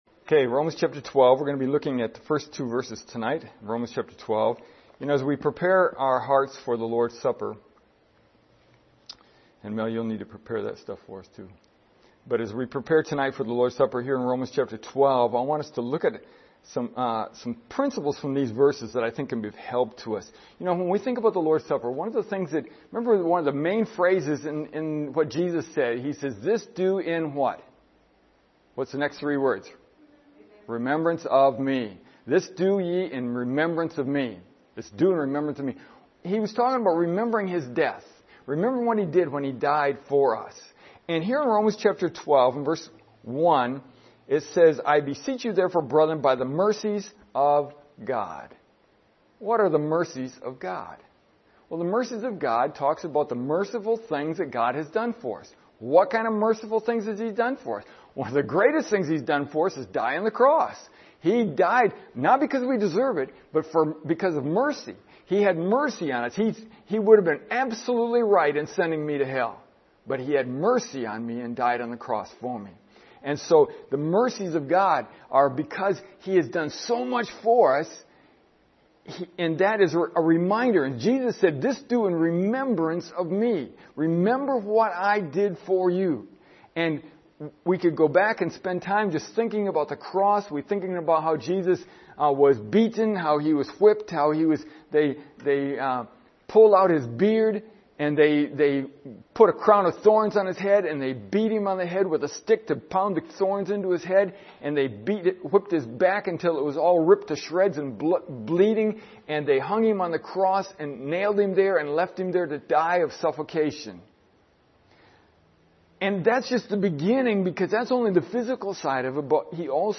This evening we are going to have the Lord’s Supper.